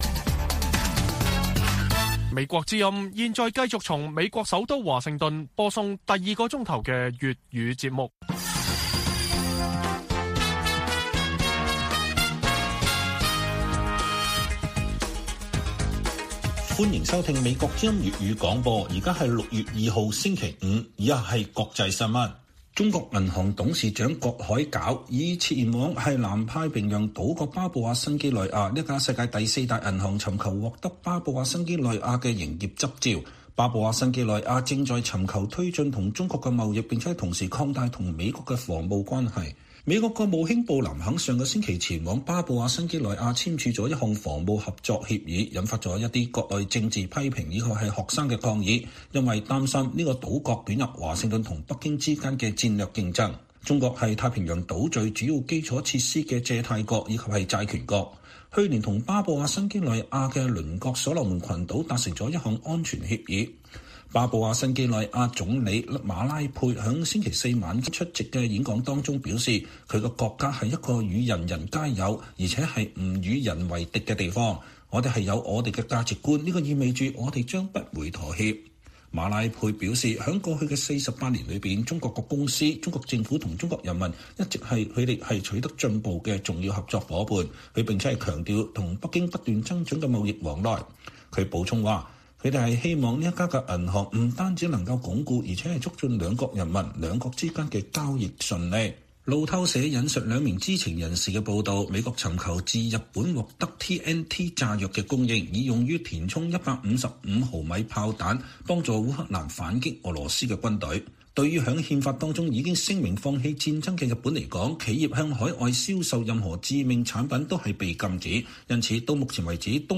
粵語新聞 晚上10-11點: 美中戰略競爭之際 中國銀行在巴新設首家分行